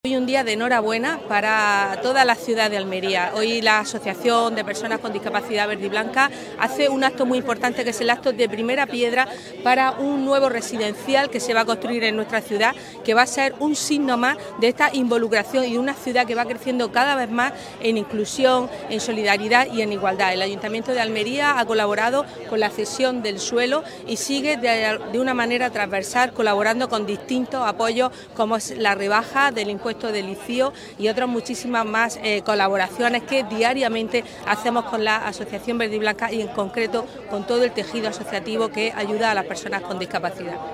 PAOLA-LAYNEZ-CONCEJALA-FAMILIA-PRIMERA-PIEDRA-CENTRO-VERDIBLANCA.mp3